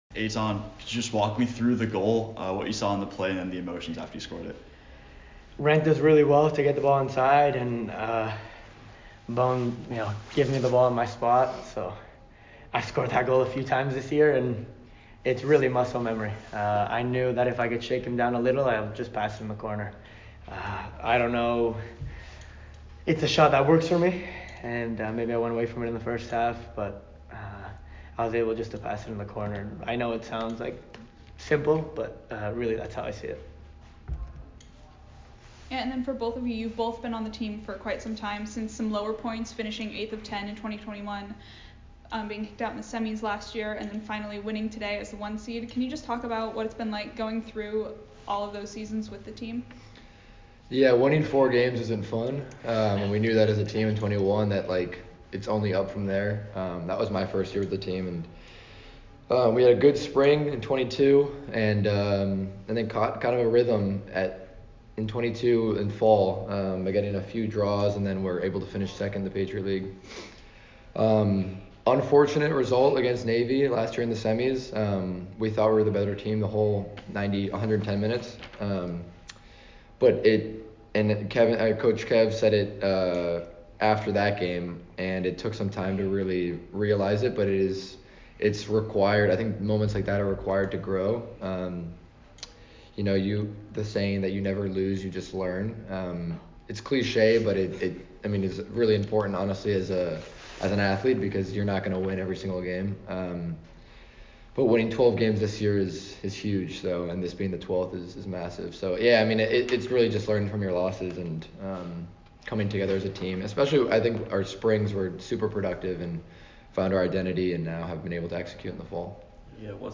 Lafayette Postgame Interview